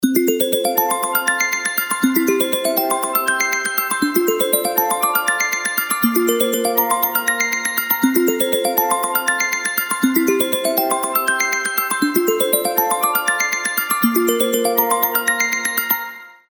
• Качество: 320, Stereo
мелодичные
без слов
звонкие